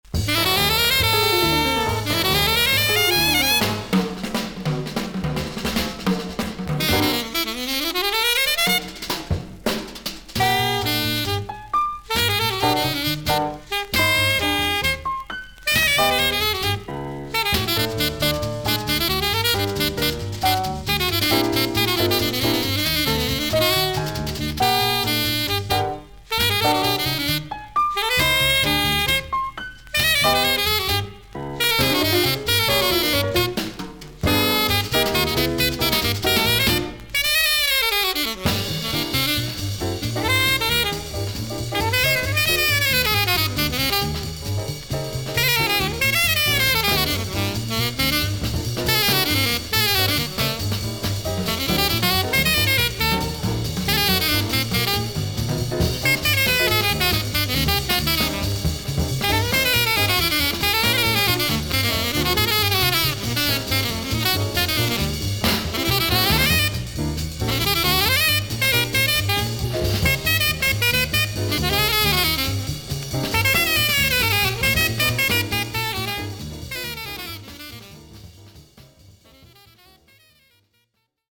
少々軽いパチノイズの箇所あり。少々サーフィス・ノイズあり。クリアな音です。
ジャズ・サックス奏者。